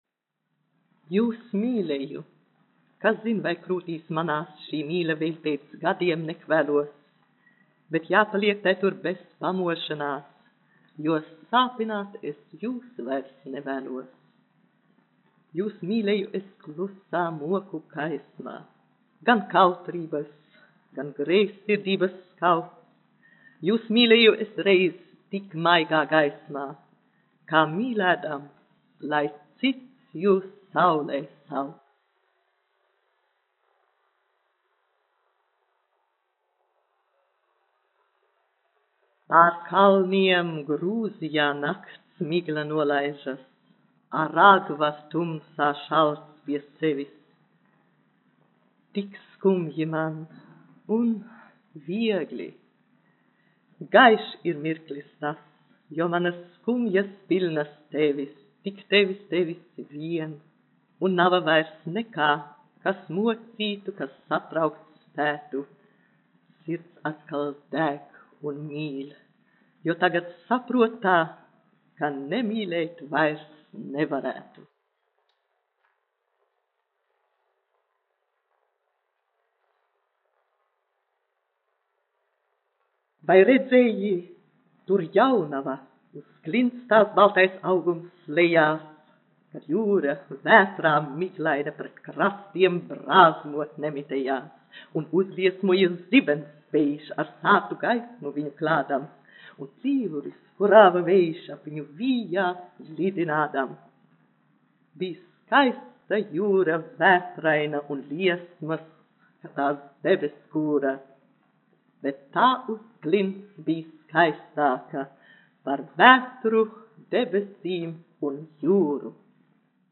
[Lilita Bērziņa lasa A. Puškina dzeju]
1 skpl. : analogs, 78 apgr/min, mono ; 25 cm
Skaņuplate
Latvijas vēsturiskie šellaka skaņuplašu ieraksti (Kolekcija)